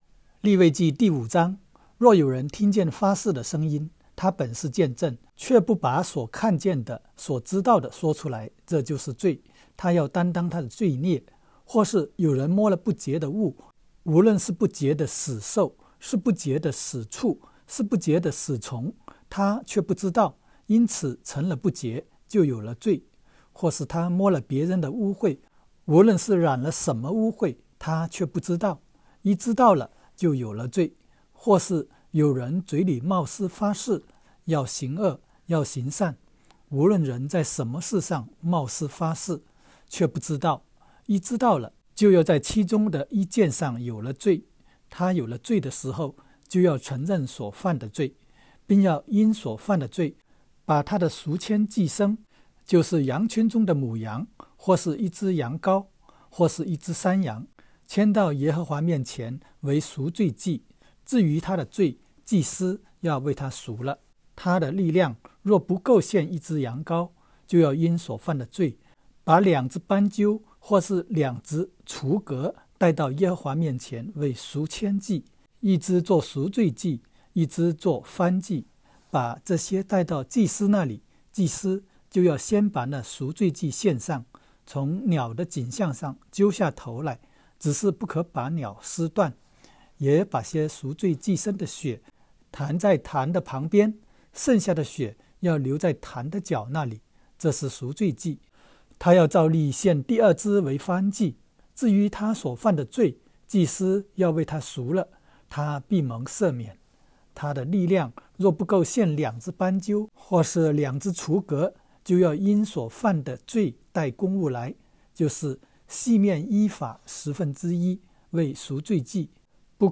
利05（经文-国）.m4a